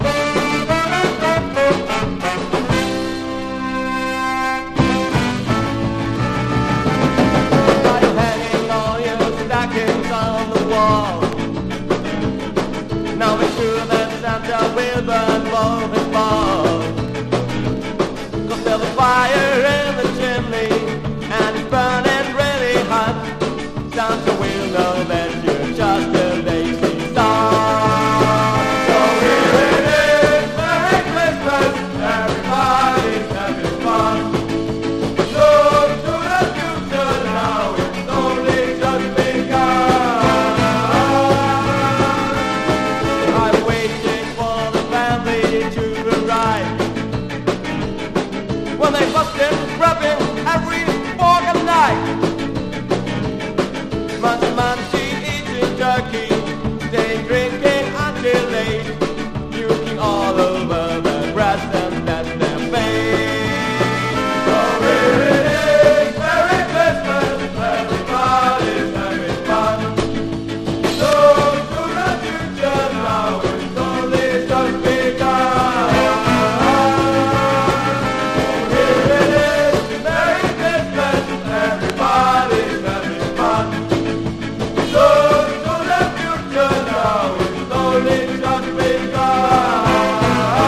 ジャーマン・ネオスカ
スカ・カヴァー